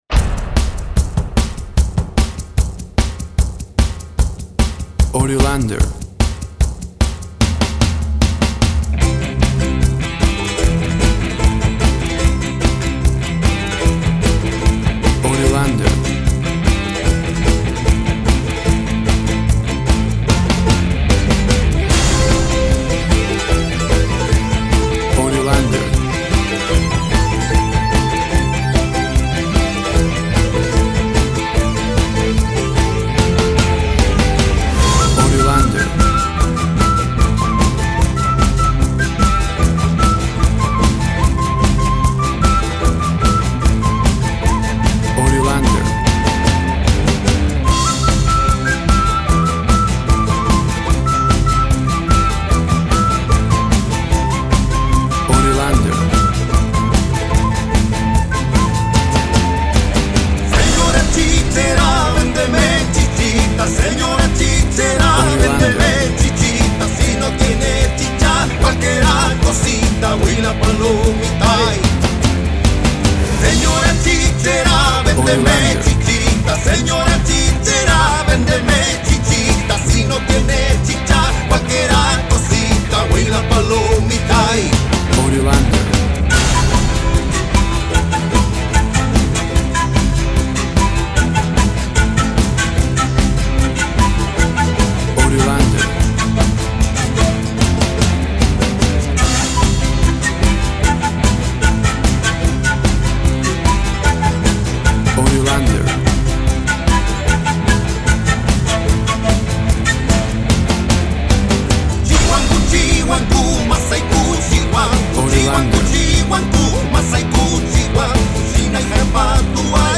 WAV Sample Rate 16-Bit Stereo, 44.1 kHz
Tempo (BPM) 140